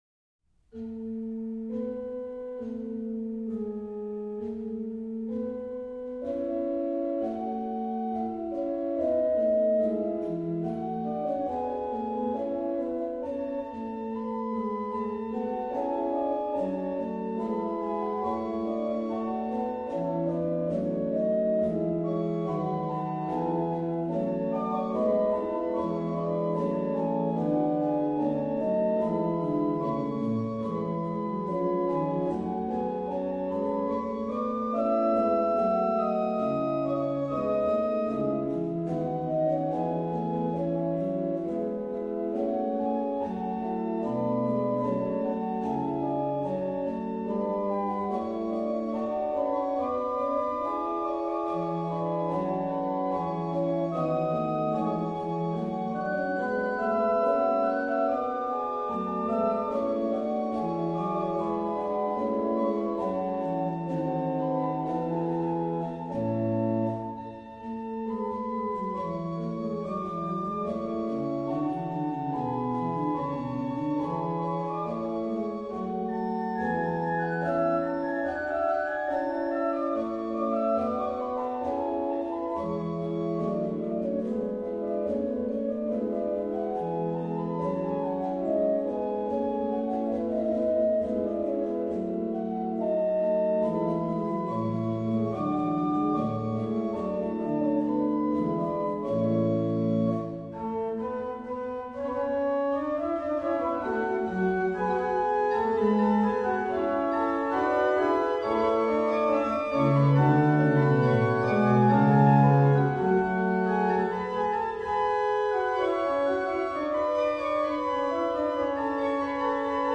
Registration   BW: Ged8, Fl4
m. 30: RP: Rfl8, Oct4